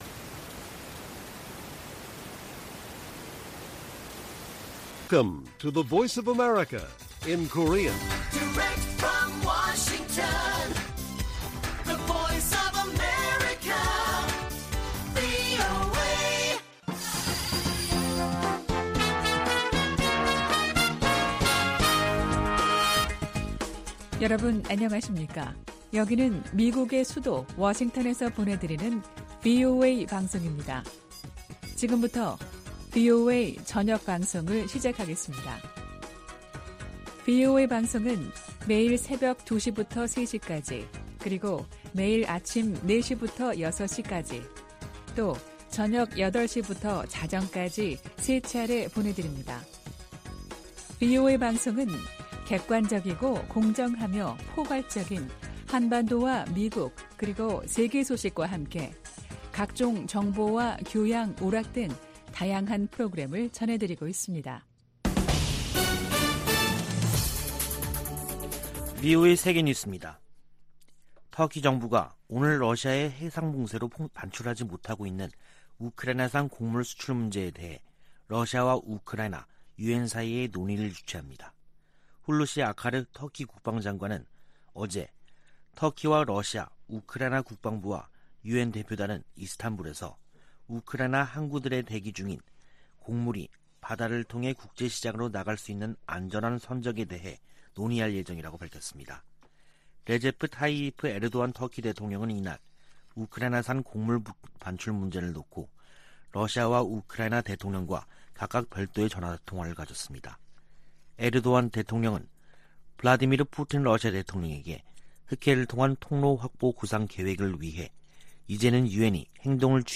VOA 한국어 간판 뉴스 프로그램 '뉴스 투데이', 2022년 7월 13일 1부 방송입니다. 전임 도널드 트럼프 미국 행정정부에서 고위 관료를 지낸 인사들이 강력한 대북 제재로, 김정은 국무위원장이 비핵화의 길로 나오도록 압박해야 한다고 말했습니다. 미 국무부 선임고문이 한국 고위 당국자들을 만나 양국 관계 강화와 국제 현안 협력 방안을 논의했습니다. 북한이 최근 방사포를 발사한 것과 관련해 주한미군은 강력한 미한 연합방위태세를 유지하고 있다고 밝혔습니다.